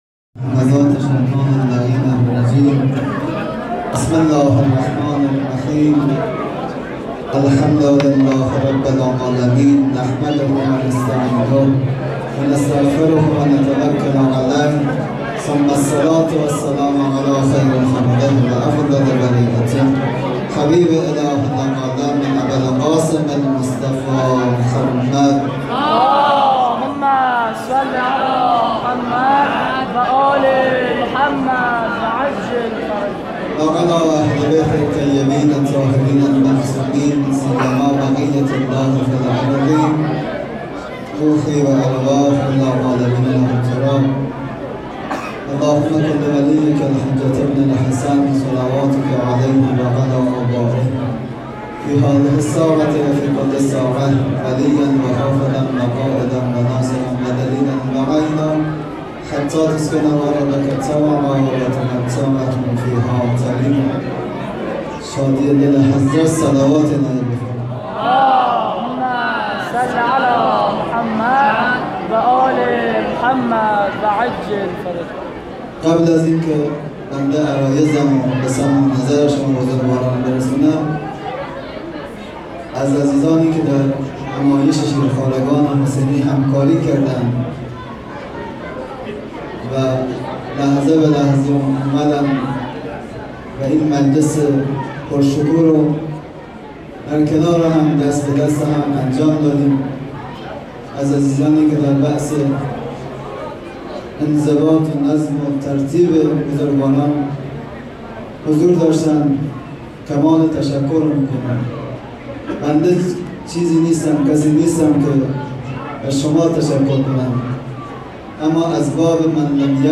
شب پنجم محرم ۹۷ هیئت میثاق الحسین(ع)سیستان
1-سخنرانی.mp3